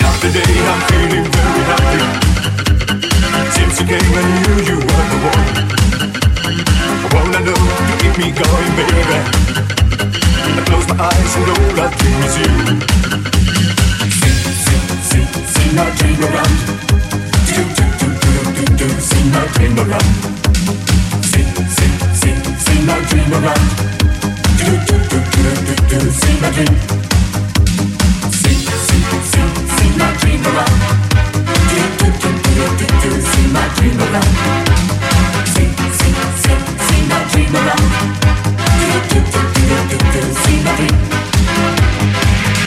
Genere: pop, rock, elettronica, successi, anni 80